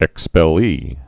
(ĕkspĕl-lē)